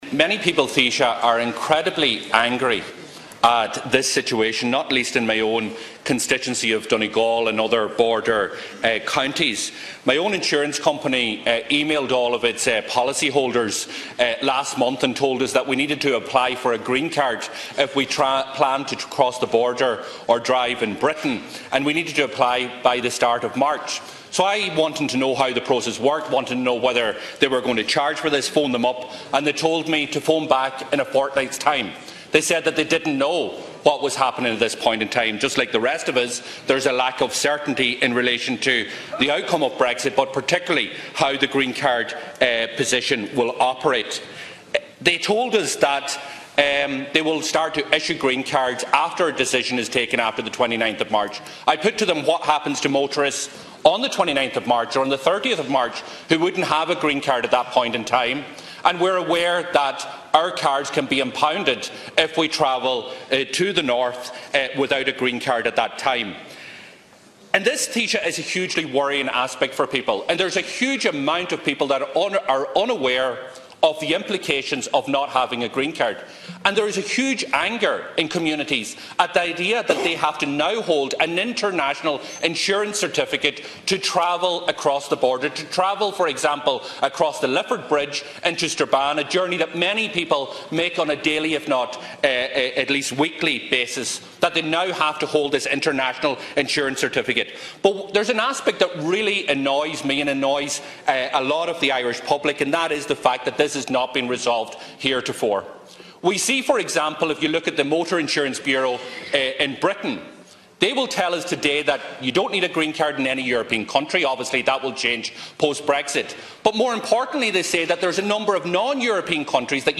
Speaking in the Dail Deputy Pearse Doherty questioned how the transport minister allowed the situation to get where it is today just a couple of weeks away from 29 March.